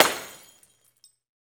Index of /90_sSampleCDs/Roland - Rhythm Section/PRC_Guns & Glass/PRC_Glass Tuned